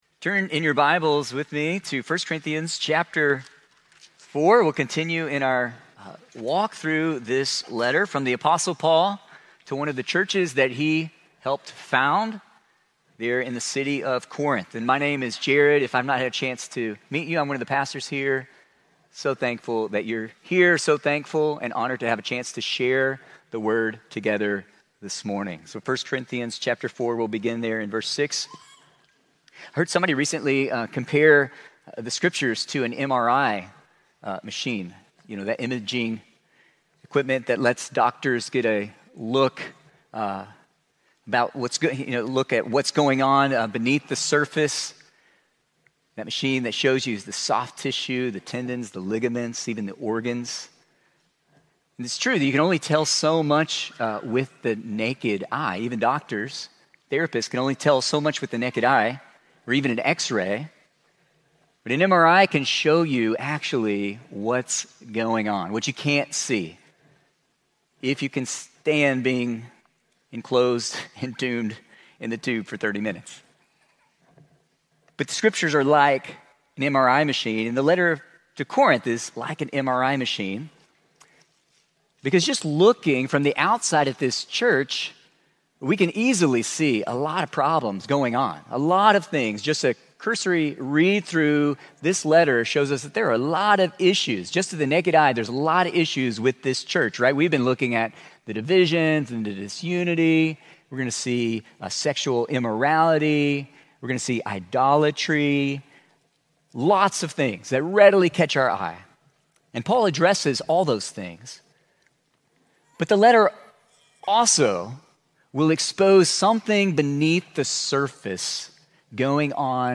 Immanuel Church - Sermons